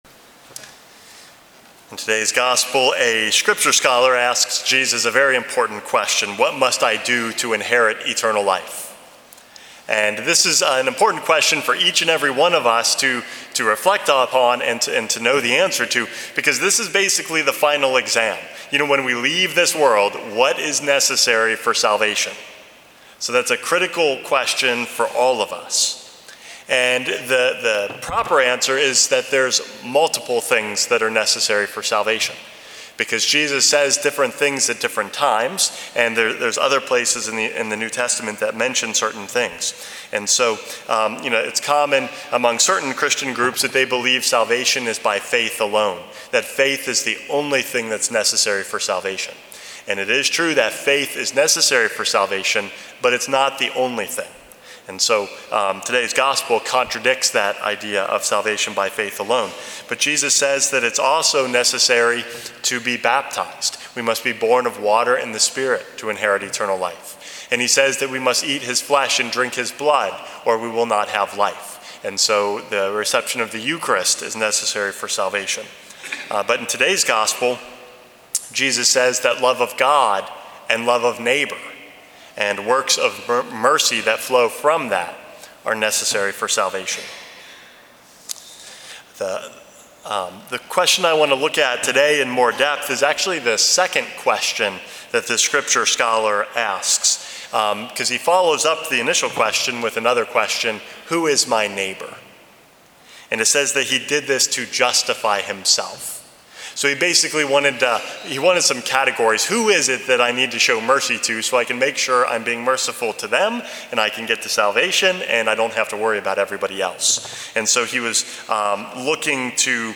Homily #457 - Ministering to Christ